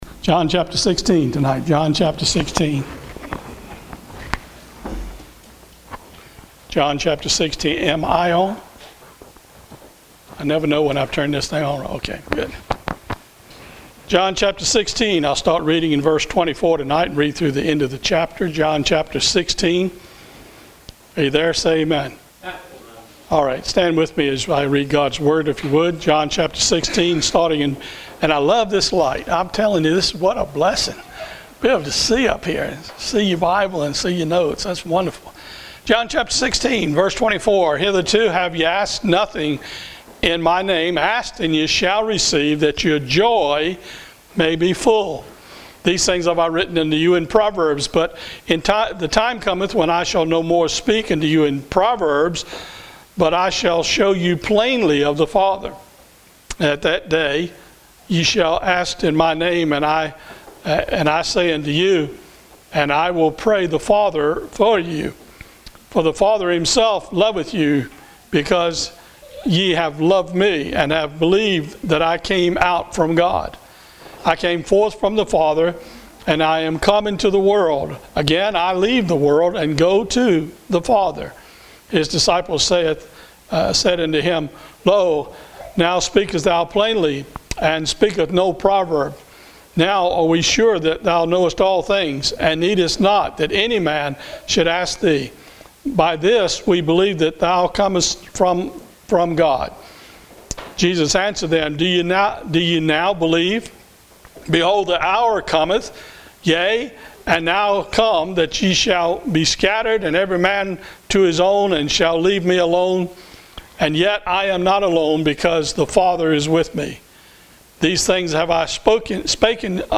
Listen to Sunday evenings message